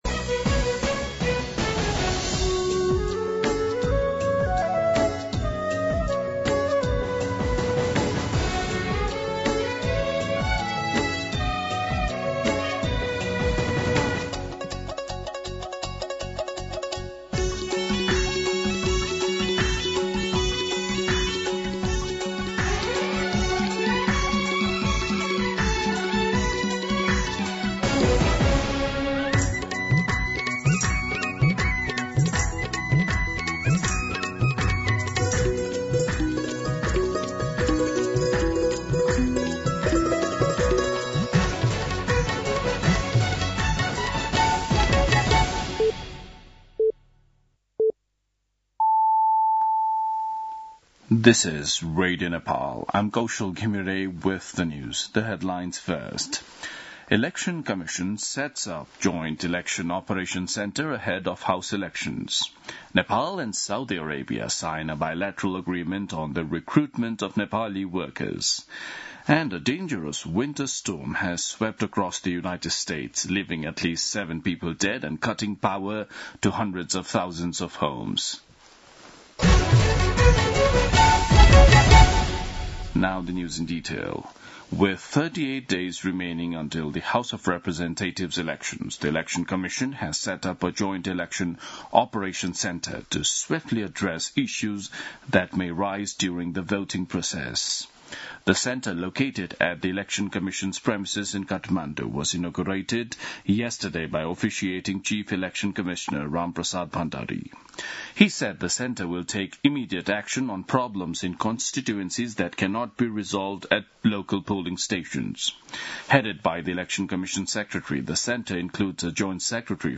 दिउँसो २ बजेको अङ्ग्रेजी समाचार : १२ माघ , २०८२